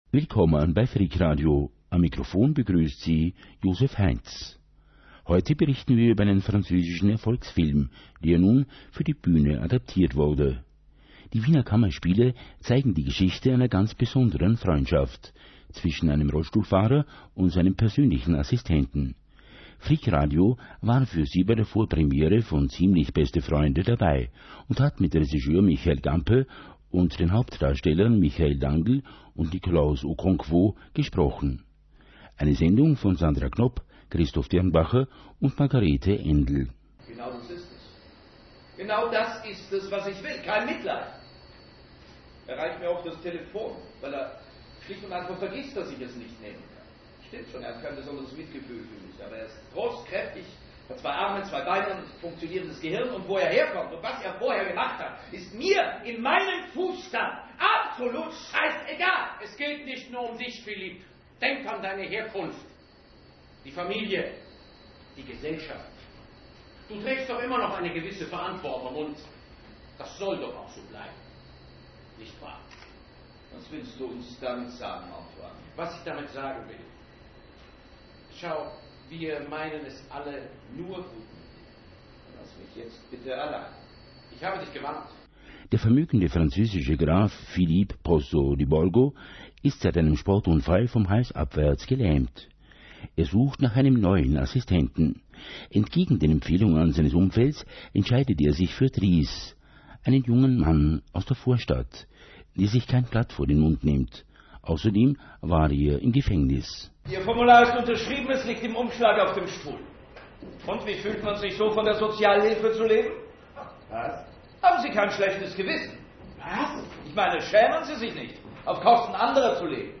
Der gleichnamige französische Kinofilm wurde nun für die Bühne adaptiert. Die Wiener Kammerspiele zeigen die Geschichte einer besonderen Freundschaft zwischen einem Rollstuhlfahrer und seinem Assistenten.